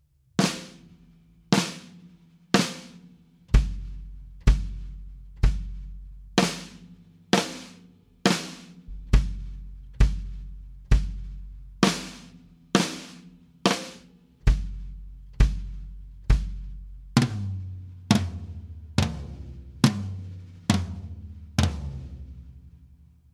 (Note to self, an iPhone 5’s built-in microphone isn’t very capable of recording drums…)
Also noteworthy, I did *not* intend to tune the toms to any certain notes or intervals. It just so happens that this was the end result when tuning each drum individually in hopes of making each one sing as much as possible.